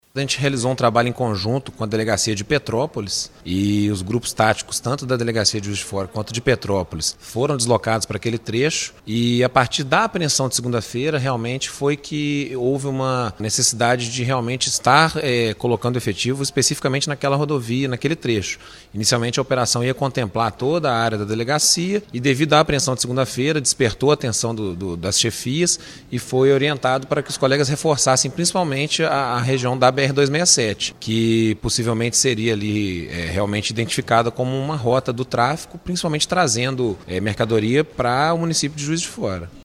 Policial rodoviário